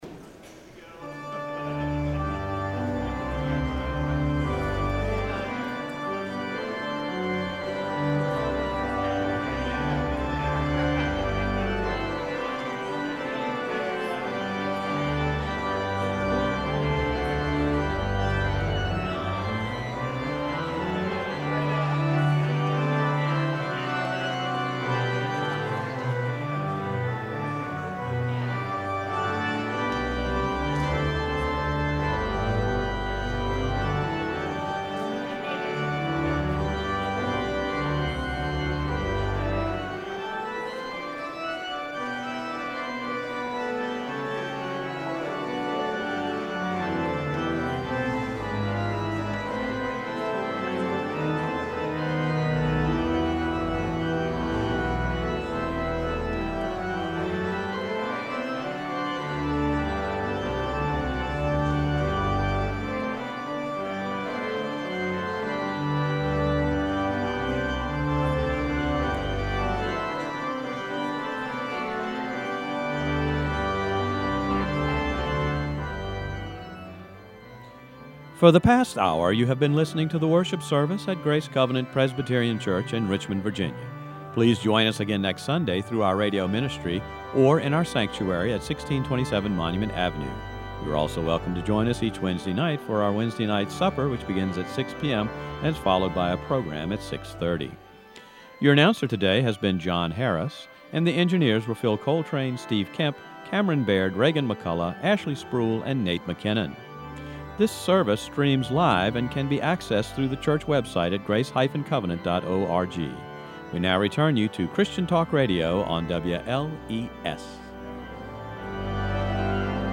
guest organist